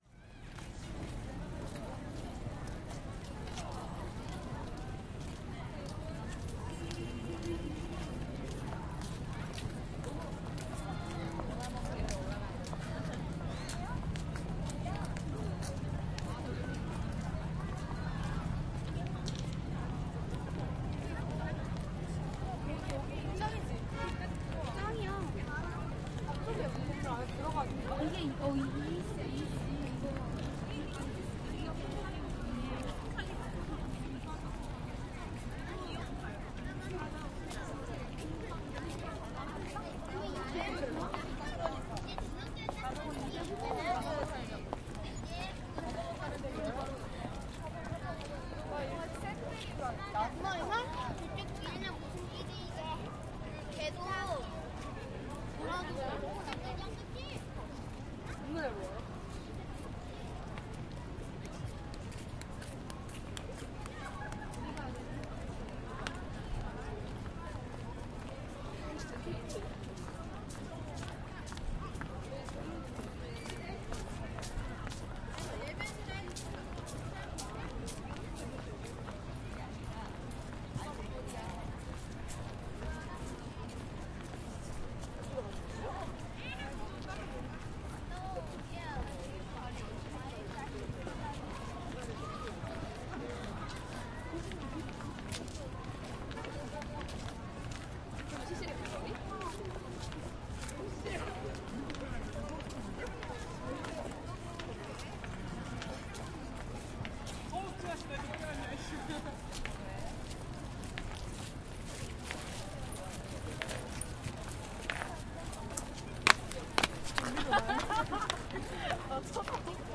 Tag: 首尔 脚步声 声音 韩语 场 - 记录 洒水 韩国